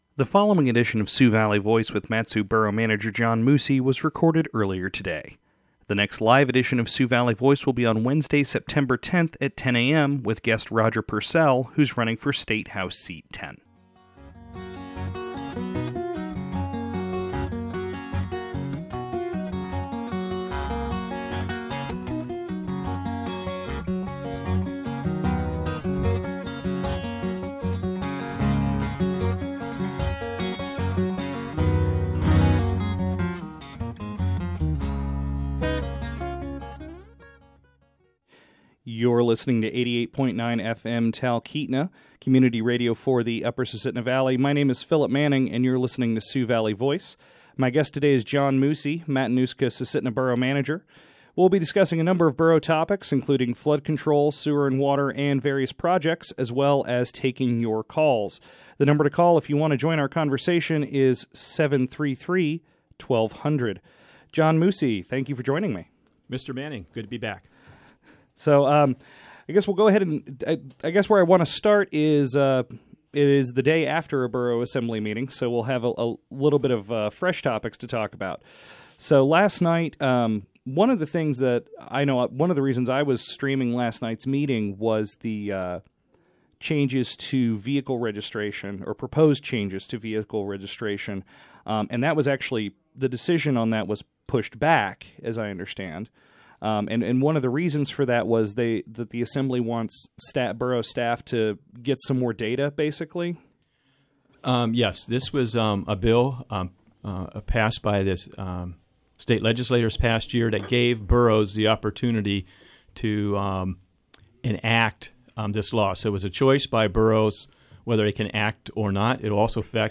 Attached is the full audio of Su-Valley Voice from August 27th, 2014 with Mat-Su Borough Manager John Moosey.